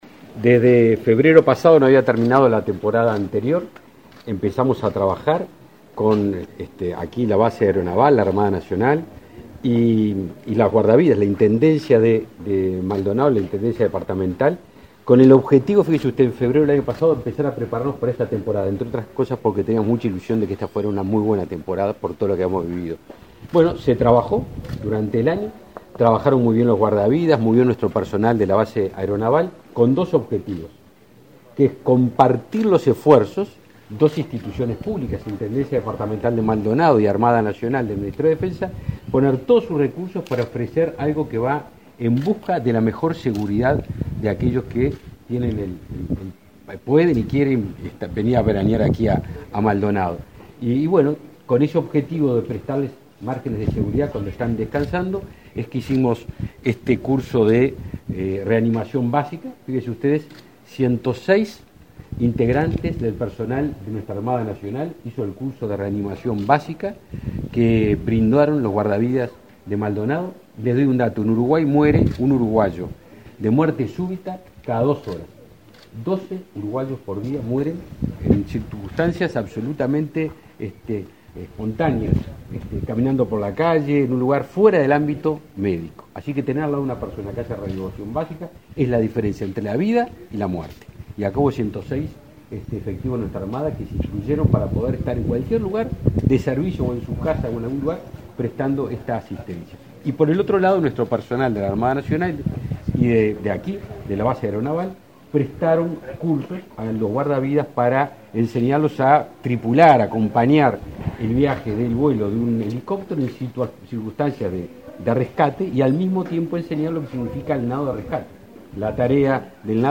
Declaraciones a la prensa del ministro de Defensa Nacional, Javier García